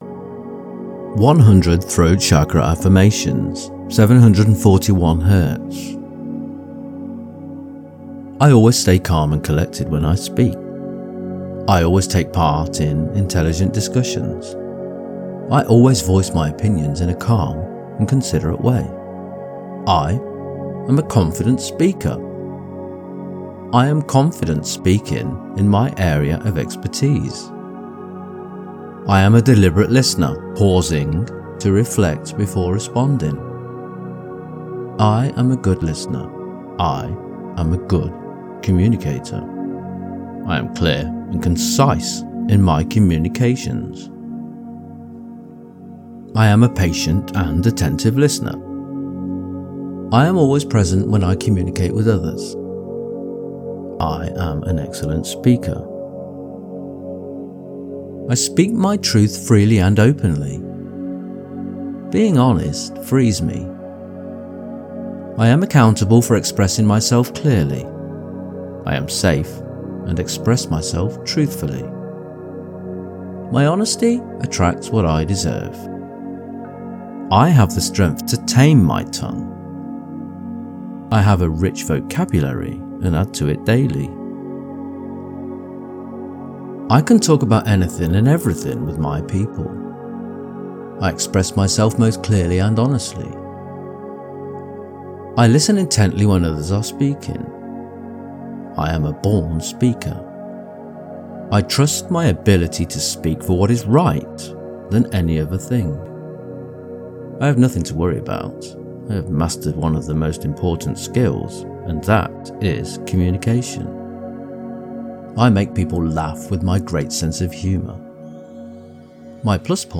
Detoxify Your Energy Field Now | Unlock Spiritual Clarity with 100 Affirmations at 741 Hz | Freedom
741-affirmations.mp3